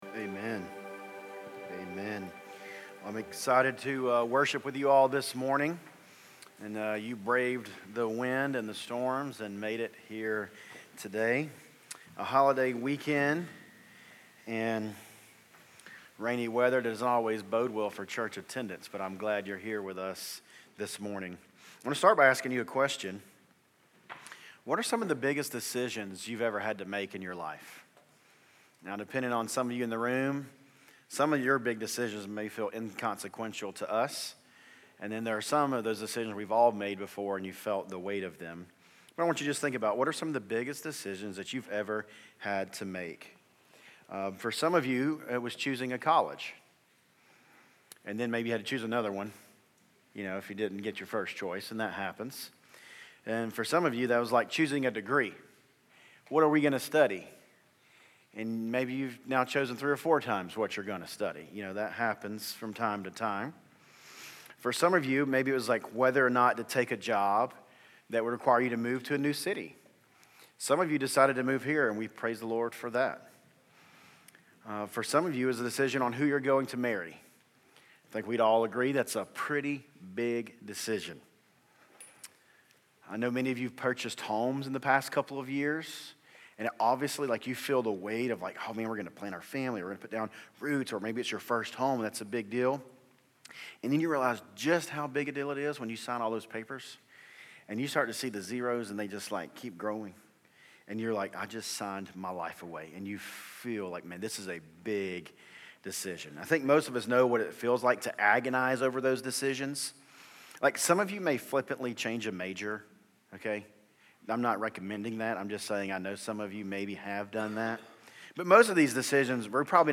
City View Church - Sermons What’s it going to be?